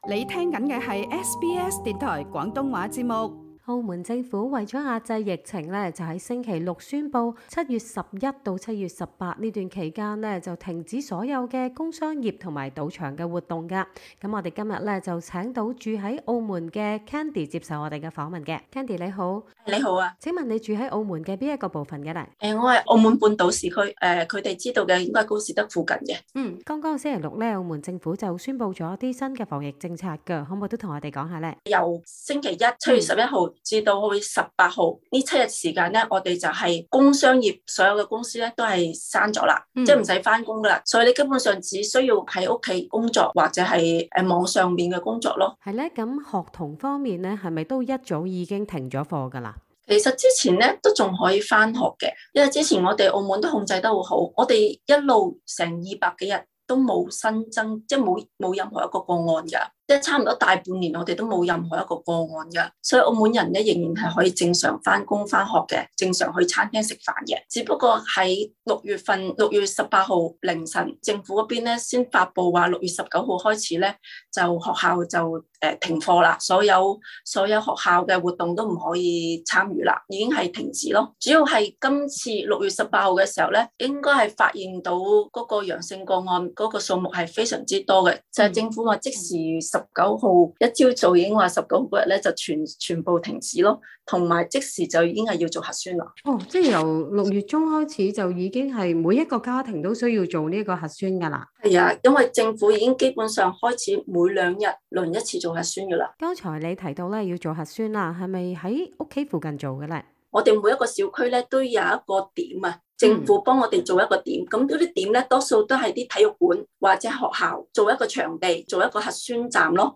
澳门疫情严峻，澳门行政长官贺一诚周六(7月9日)颁布由今日(11日)凌晨开始至下周一暂停包括赌场在内所有工商业活动公司及场所运作，但维持社会及居民生活所需的公司或场所则不受影响。SBS广东话访问了当地居民了解最新发展。